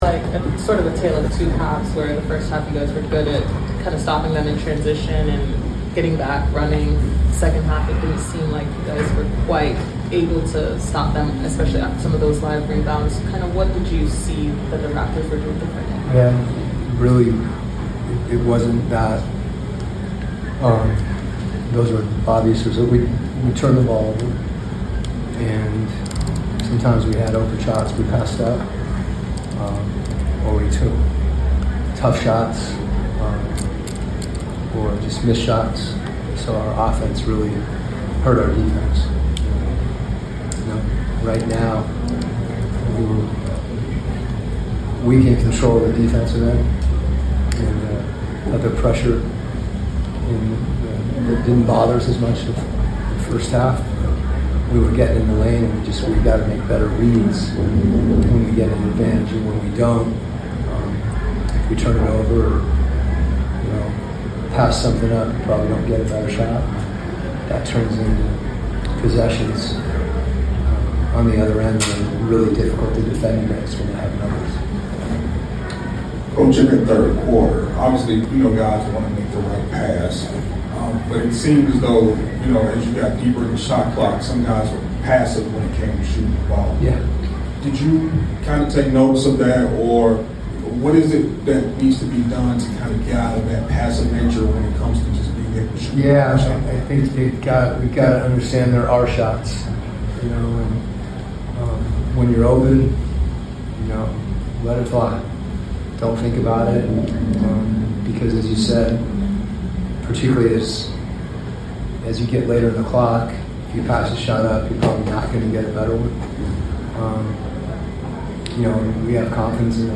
Atlanta Hawks Coach Quin Snyder Postgame Interview after losing to the Toronto Raptors at State Farm Arena.